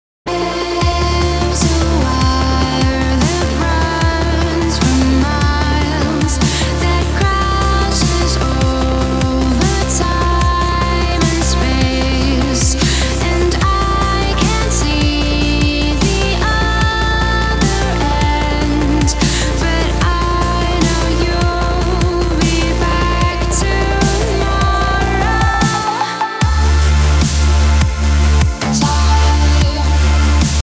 • Качество: 320, Stereo
Melodic dubstep
красивый женский голос
космические
красивый женский вокал
Стиль: electronic pop, electro, melodic dubstep